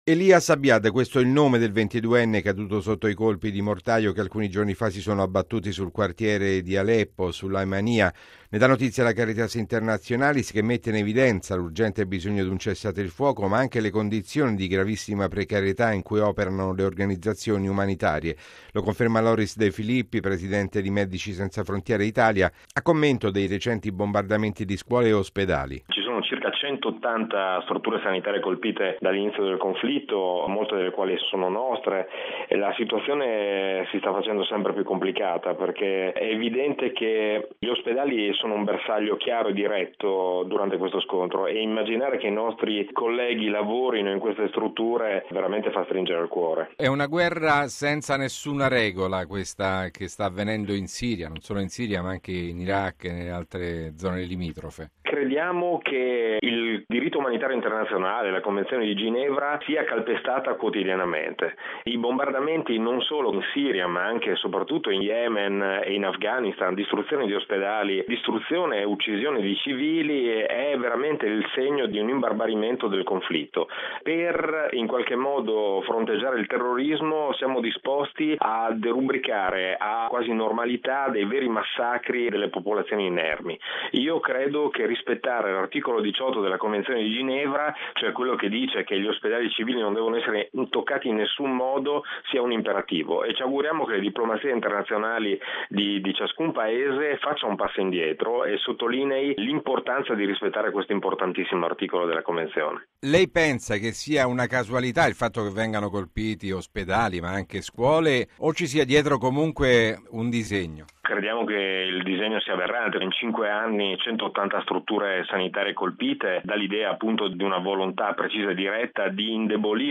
Intanto dal terreno la notizia dell’uccisione ad Aleppo di un volontario della Caritas. Il servizio